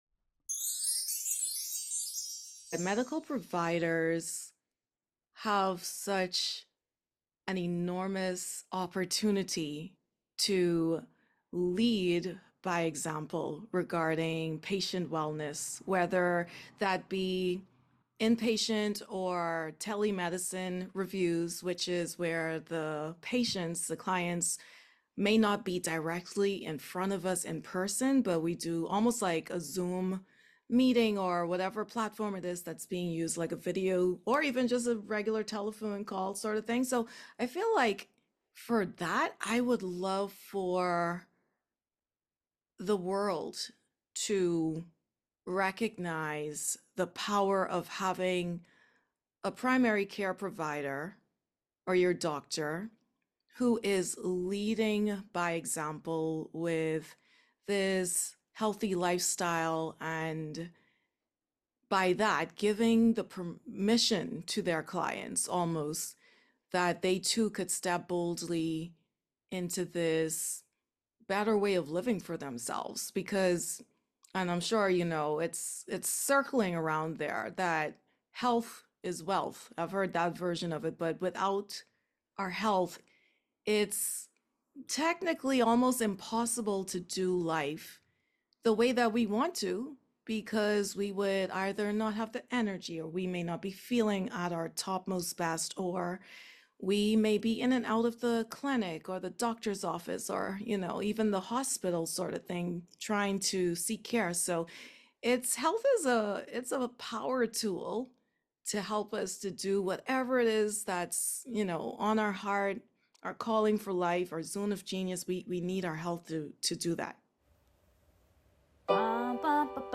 I think it's a combination of her calm, her wisdom and her Caribbean accent.